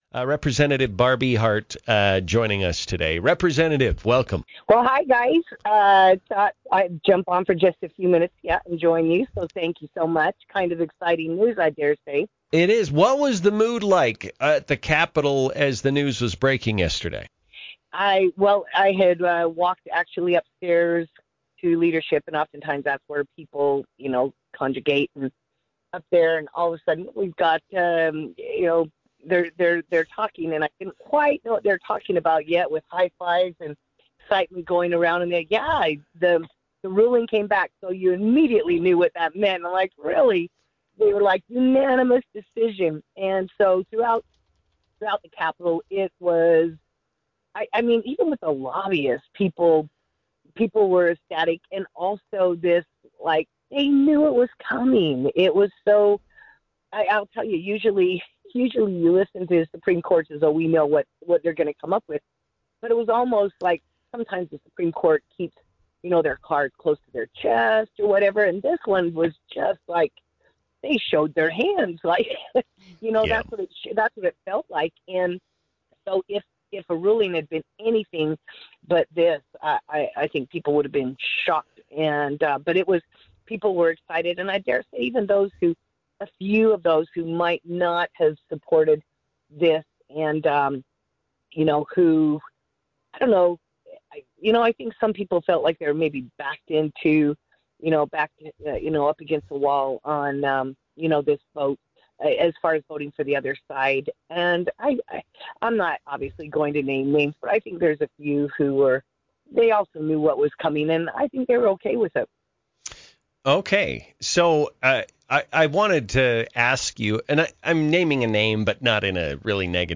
INTERVIEW: Rep. Barb Ehardt- HB93 Ruling, Anti-discrimination, Article V Conv - Newstalk 107.9